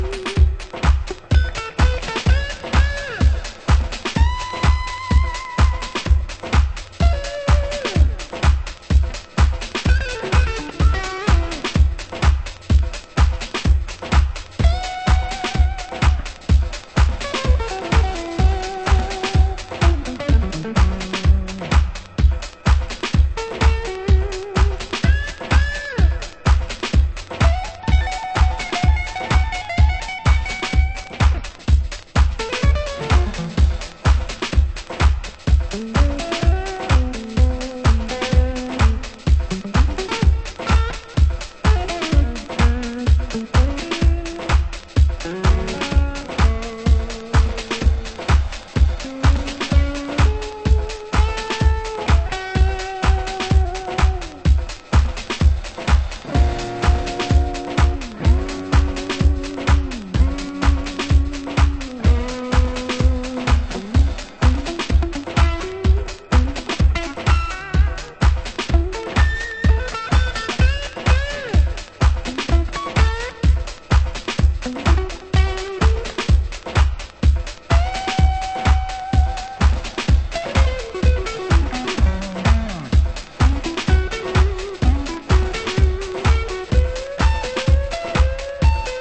HOUSE MUSIC
(Guitar Mix) 　盤質：盤面綺麗ですが、少しチリパチノイズ有